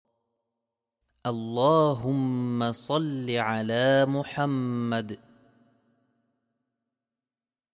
invocation-minimum.mp3